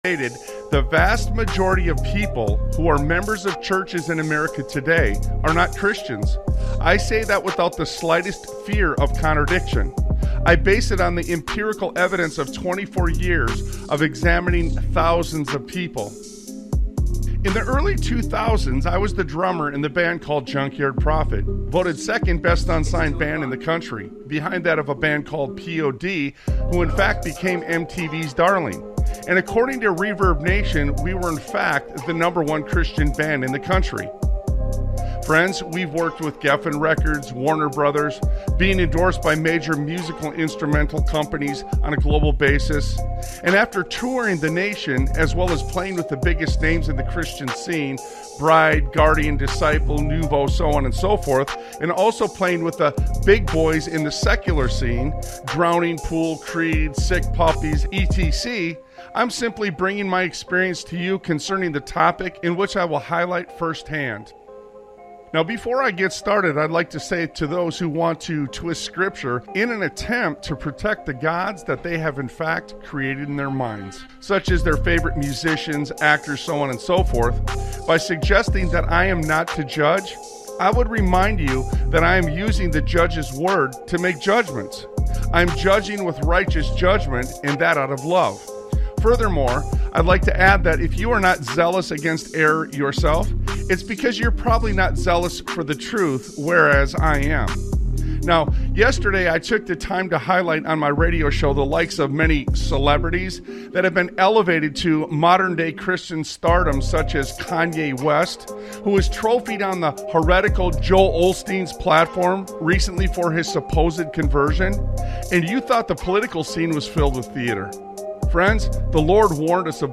Talk Show Episode, Audio Podcast, Sons of Liberty Radio and Better To Be Divided By The Truth Than United In Error on , show guests , about Better To Be Divided By The Truth Than United In Error,Divided by Truth,A Critique of Modern Apostasy and Political Corruption,A critique of modern religious apostasy,political corruption,Religious Deception & Stardom,The Epstein Blackmail Operation,Institutional Failure & Justice,The Duty of Resistance,The Crisis of Faith and Celebrity, categorized as Education,History,Military,News,Politics & Government,Religion,Christianity,Society and Culture,Theory & Conspiracy